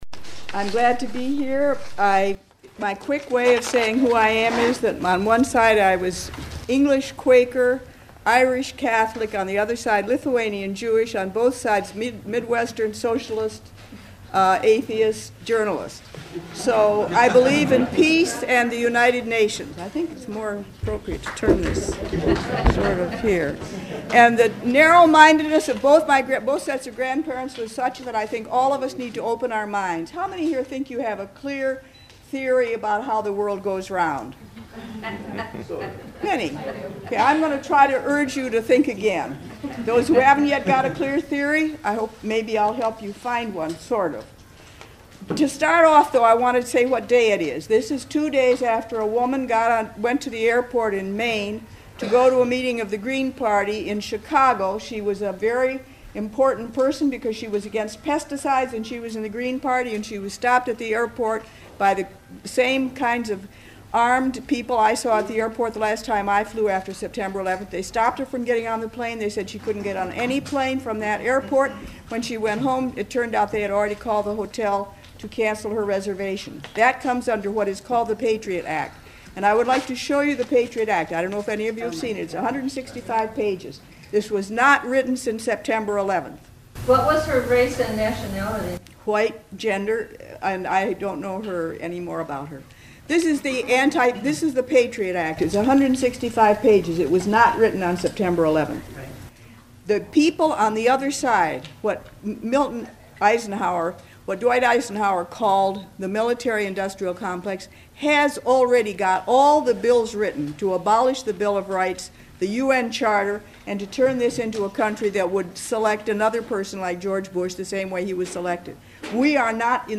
The East Bay Coalition Against the War held a forum at Laney College in Oakland on Nov. 4.